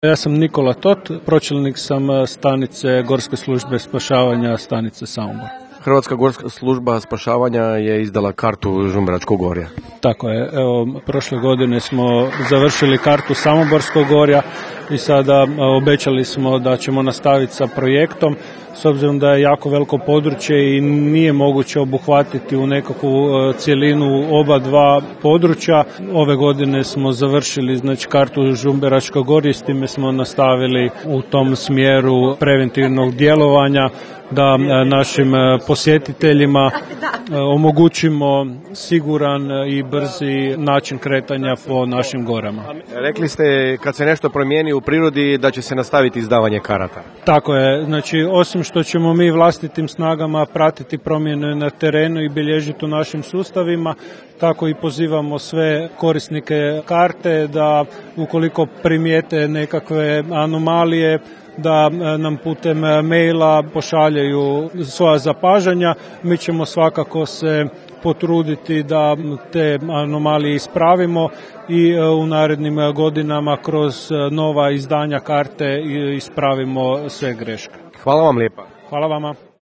Evo što su tom prilikom našem novinaru rekli njezini izdavači: